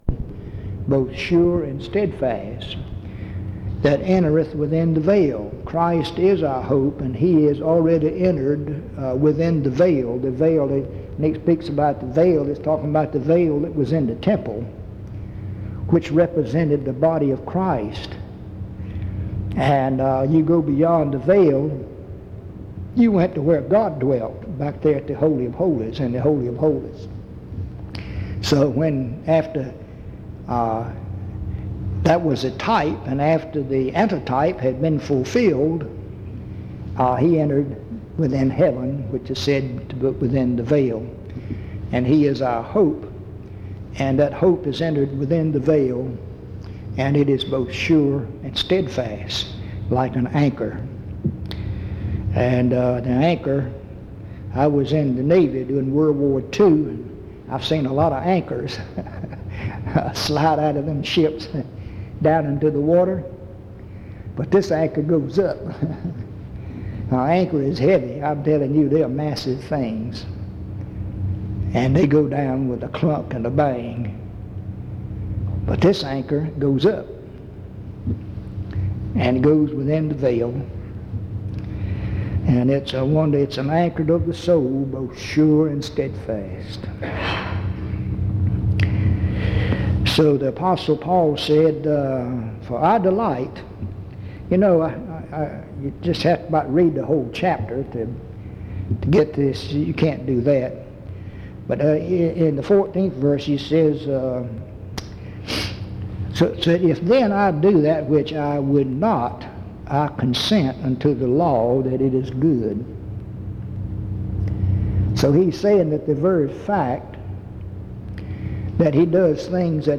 In Collection: Reidsville/Lindsey Street Primitive Baptist Church audio recordings Thumbnail Titolo Data caricata Visibilità Azioni PBHLA-ACC.001_064-A-01.wav 2026-02-12 Scaricare PBHLA-ACC.001_064-B-01.wav 2026-02-12 Scaricare